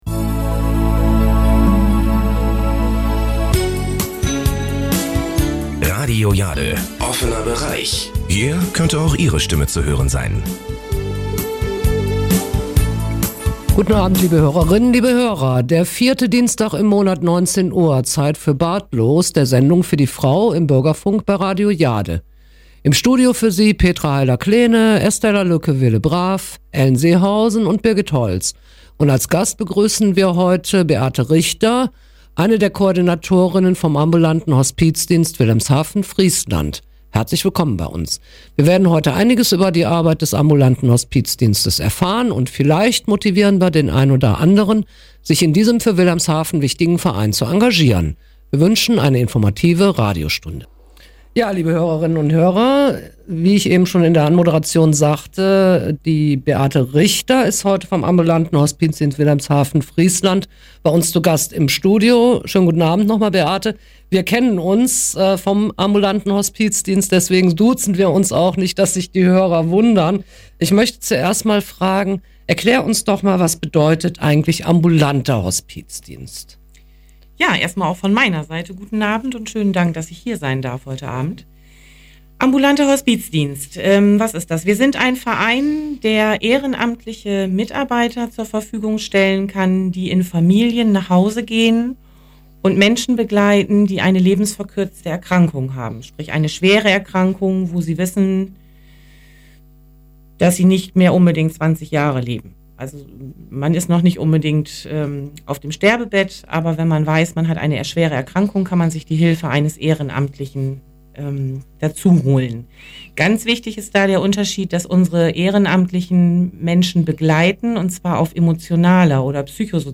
Der Ambulante Hospizdienst – Interview auf Radio Jade (27.11.18)
Interview.mp3